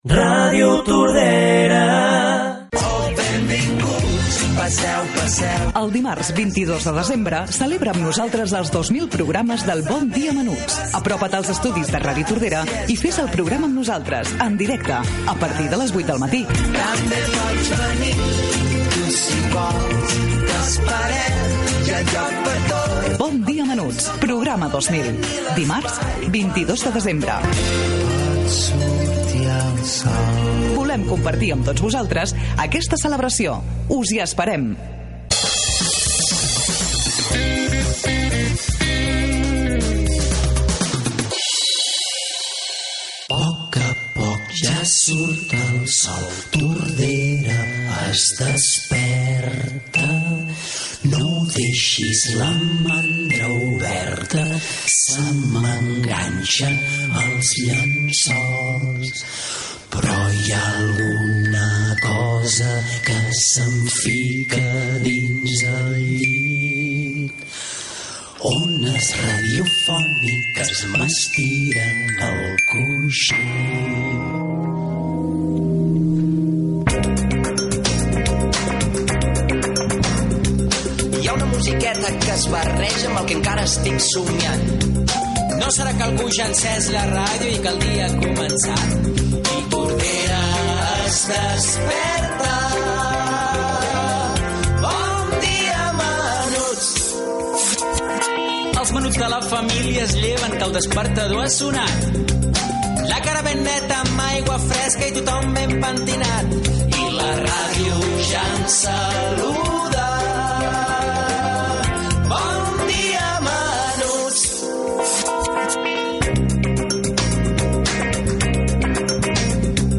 Indicatius de la ràdio i dels 2000 programes de "Bon dia menuts", sintonia del programa, presentació amb el record dels objectius del programa, comentaris de l'equip, Whats App de la ràdio, missatges rebuts, participació d'alguns infants Gènere radiofònic Infantil-juvenil